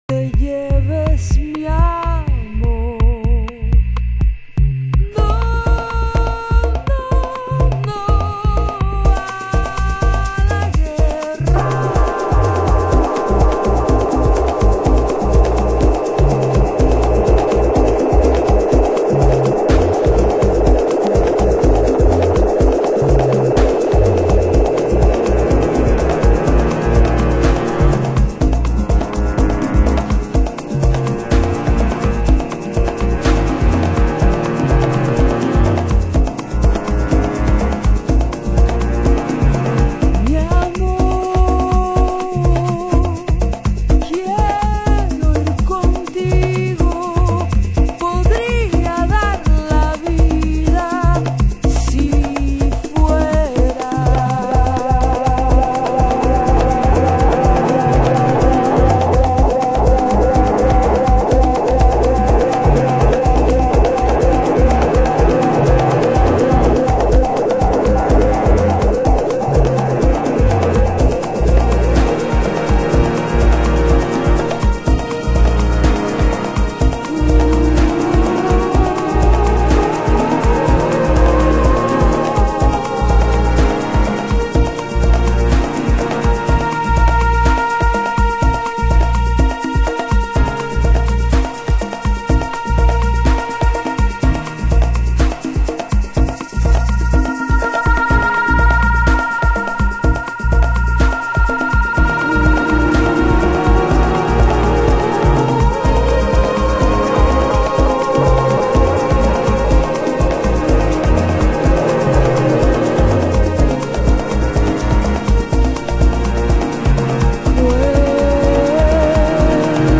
HOUSE MUSIC